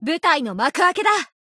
贡献 ） 协议：Copyright，作者： Cygames ，其他分类： 分类:富士奇石语音 您不可以覆盖此文件。